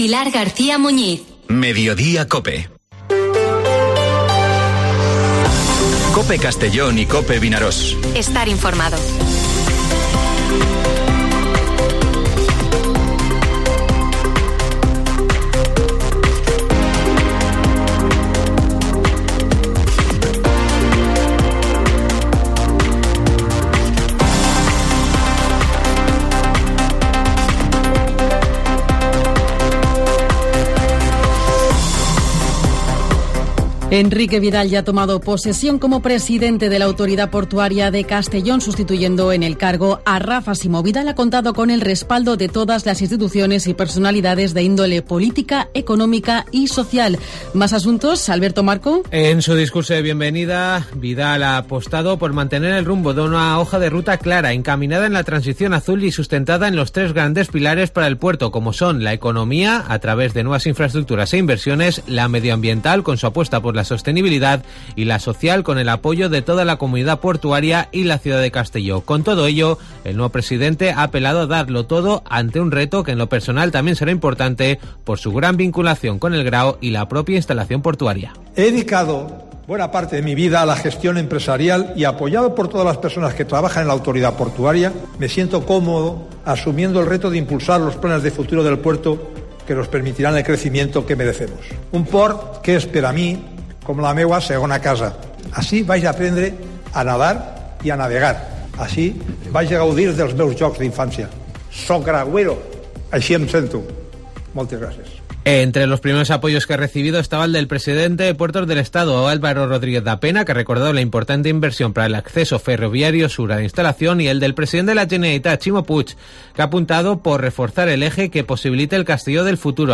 Informativo Mediodía COPE en Castellón (18/04/2023)